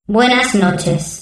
voz nș 0141